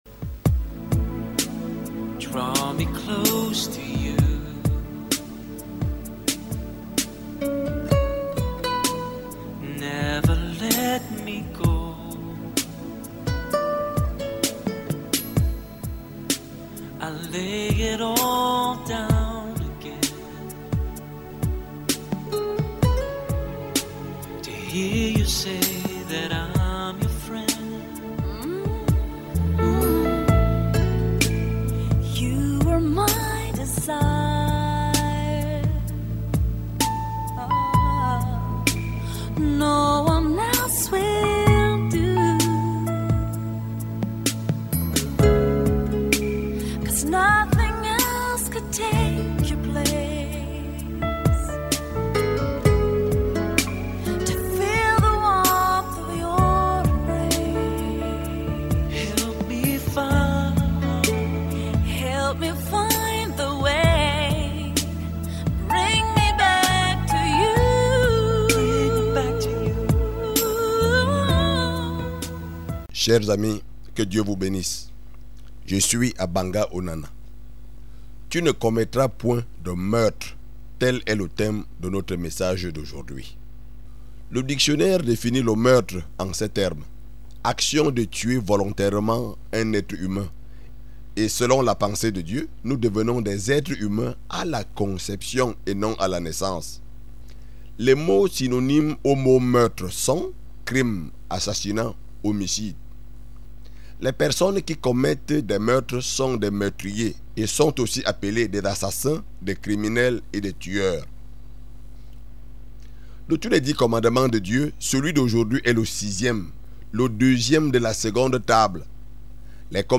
Évangéliste
Obtenez les réponses à vos questions et plus encore en écoutant cet enseignement.